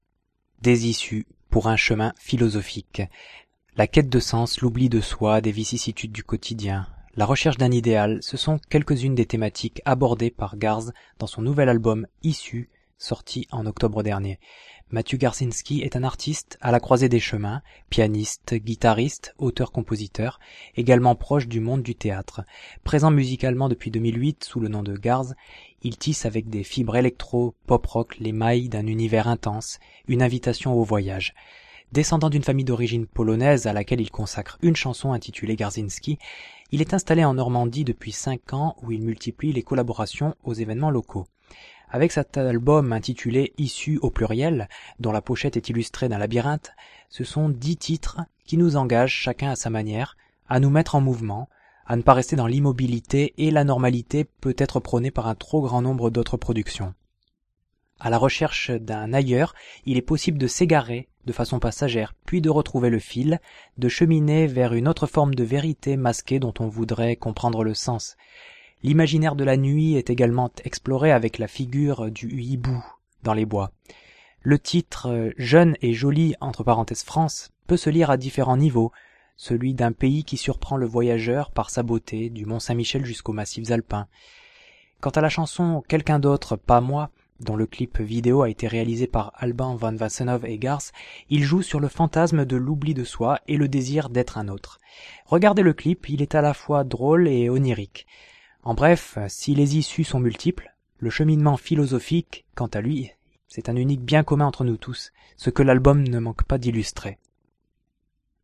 pianiste, guitariste, auteur-compositeur